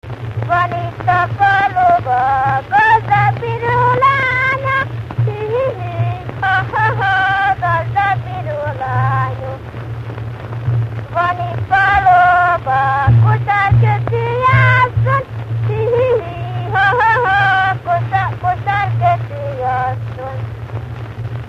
Dunántúl - Vas vm. - Pankasz
Stílus: 8. Újszerű kisambitusú dallamok
Szótagszám: 6.6.6.6
Kadencia: V (5) X V